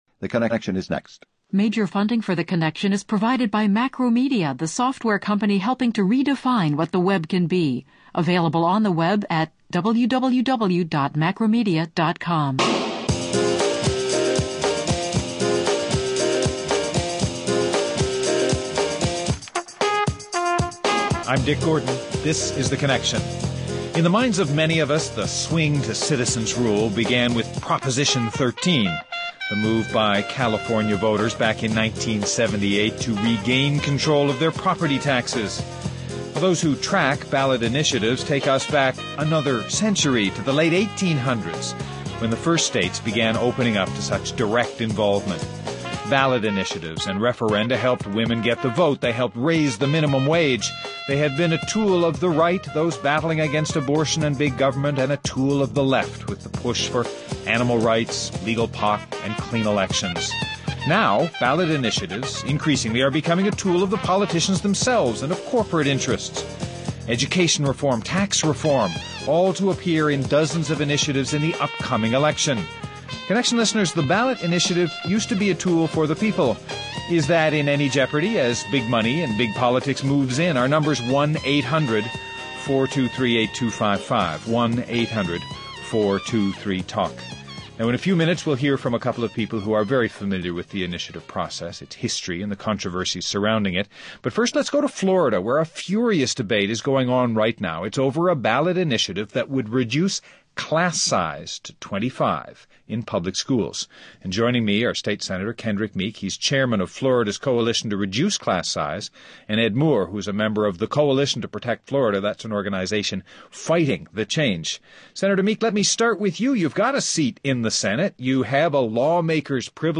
Lane Shetterly, member of the Oregon House of Representatives